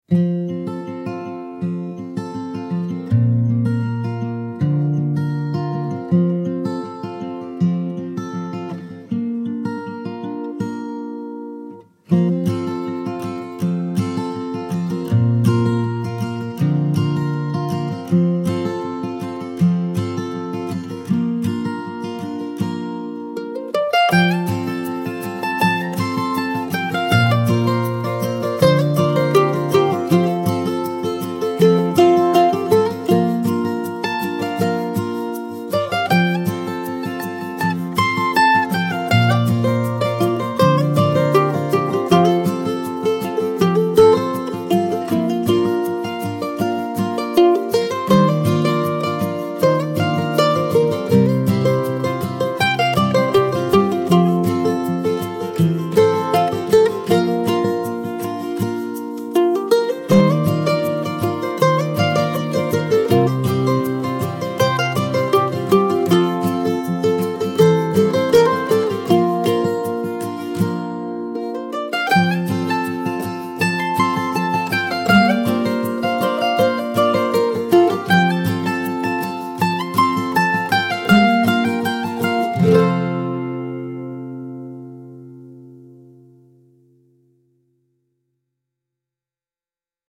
warm folk duet with mandolin and acoustic guitar in a rustic style